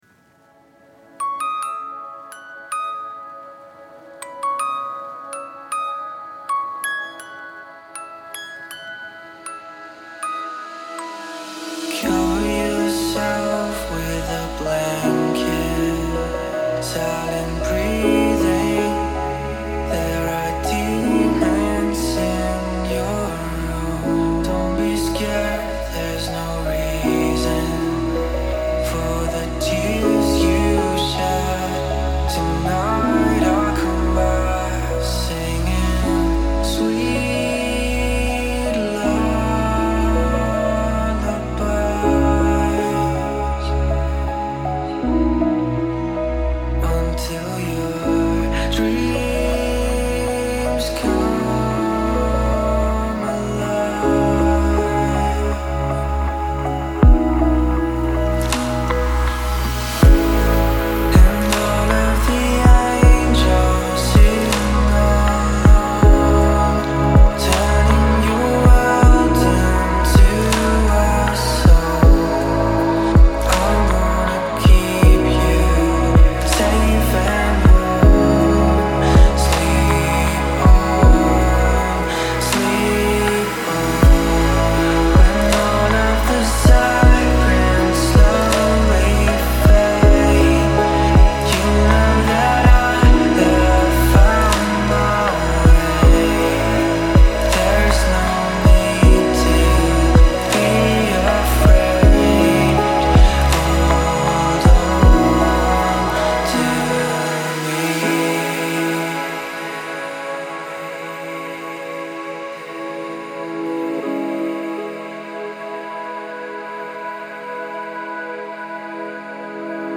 это атмосферная композиция в жанре электронного попа